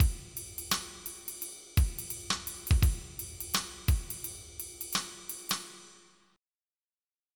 MIDI Music File
jazz.mp3